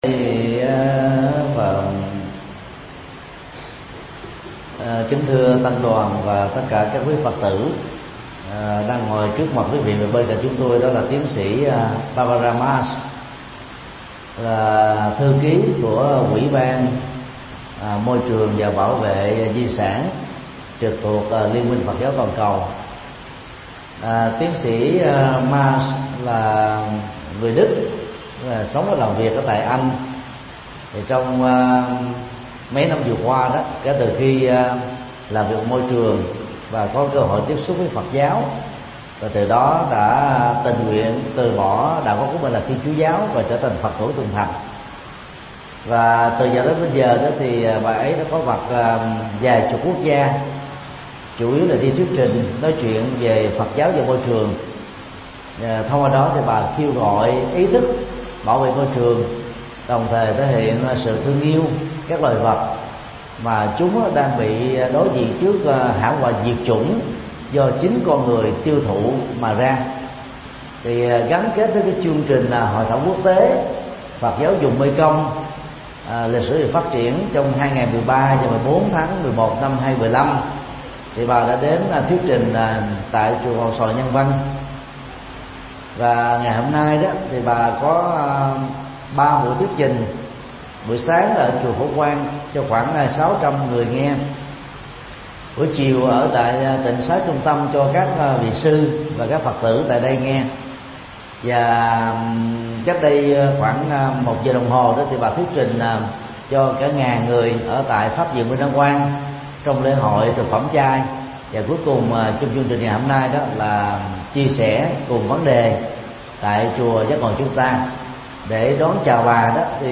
Pháp âm Phật giáo và thiên nhiên: Yêu thương con người và động vật (Bản LIVE STREAM) - Thầy Thích Nhật Từ Tường thuật trực tiếp tại chùa Giác Ngộ – 92 Nguyễn Chí Thanh